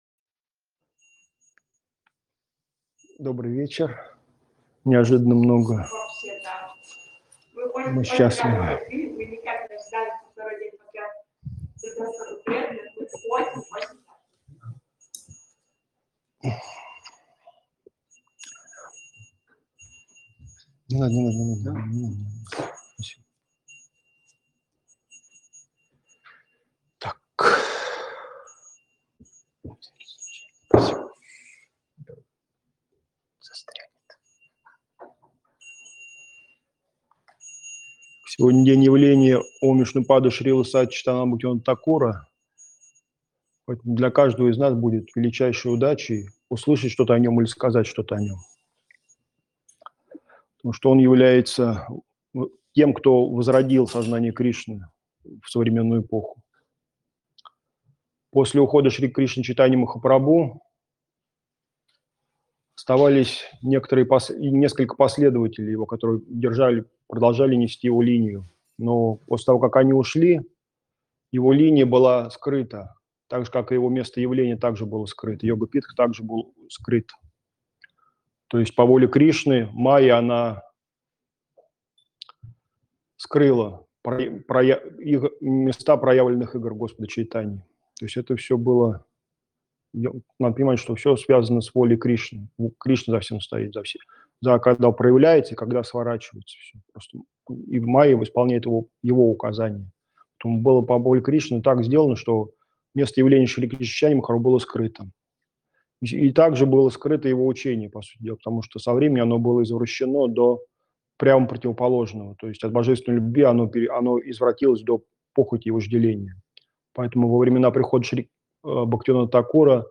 Место: Кисельный (Москва)
Лекции полностью